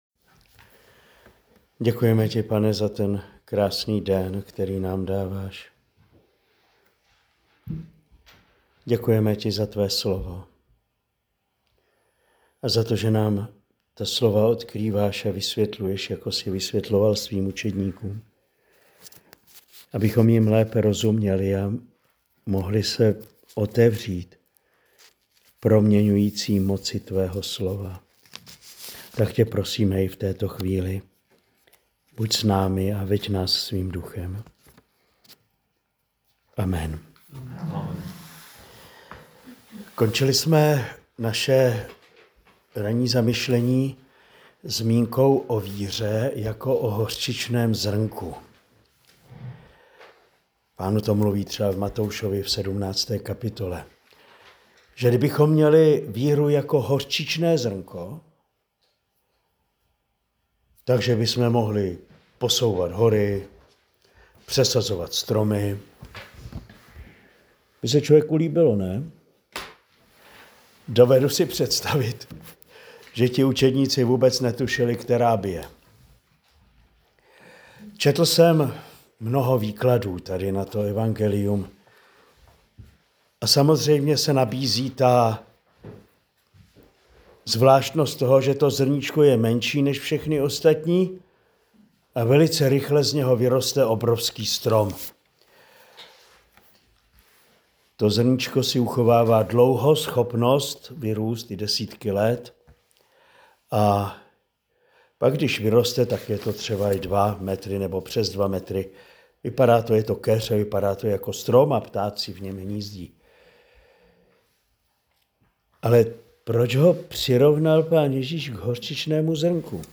Třetí promluva z duchovní obnovy pro manžele v Kostelním Vydří v únoru 2025.